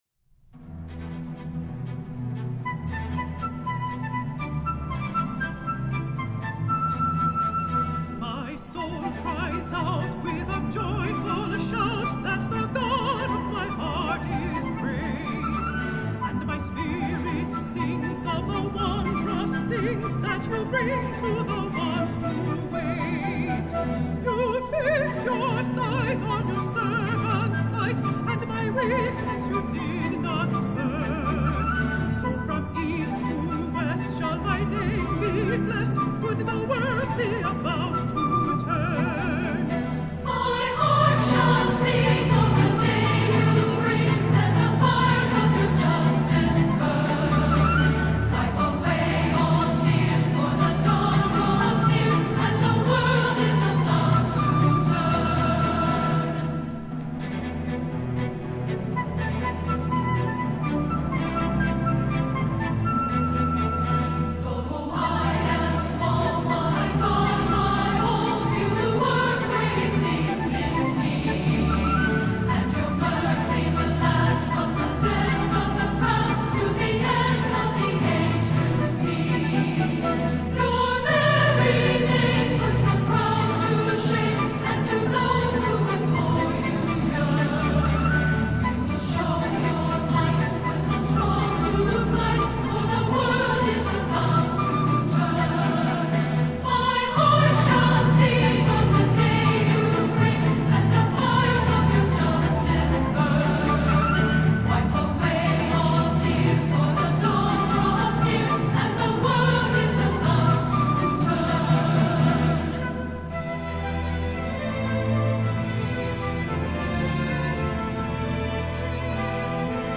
Voicing: SSA, SSA/SSAA, Solo